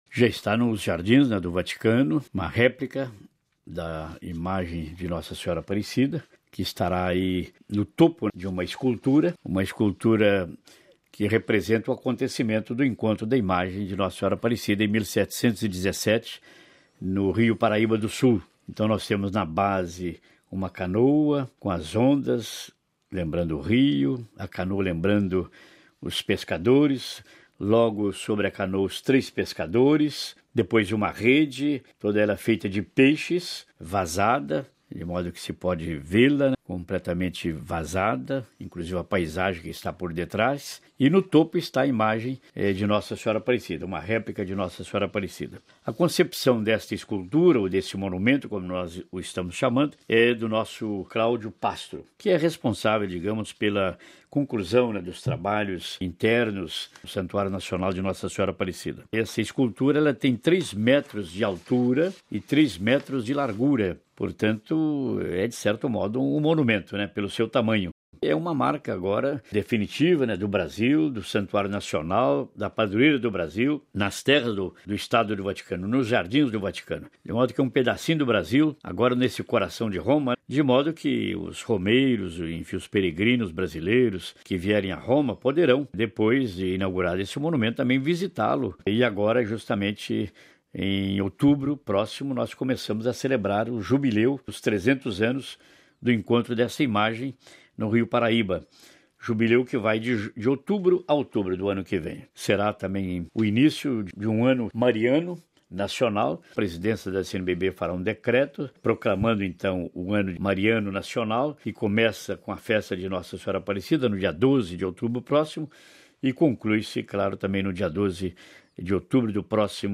Em entrevista à Rádio Vaticano, Dom Raymundo explicou como será o memorial.